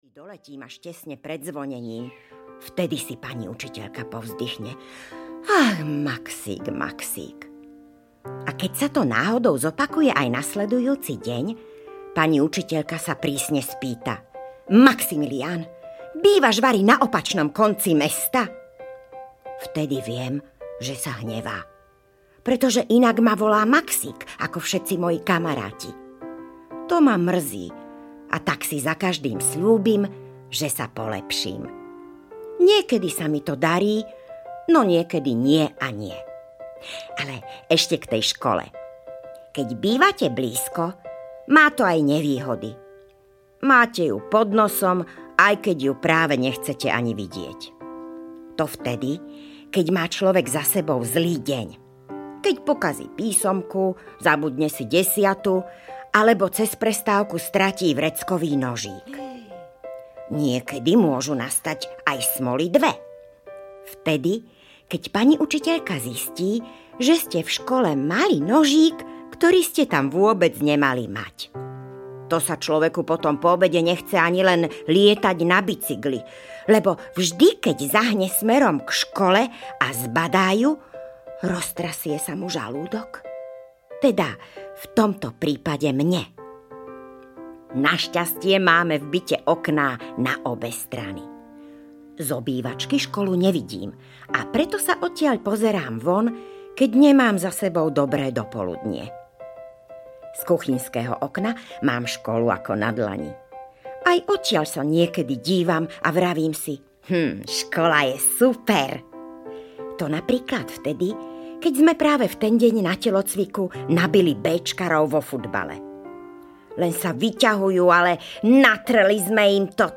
Maxík a kamaráti audiokniha
Ukázka z knihy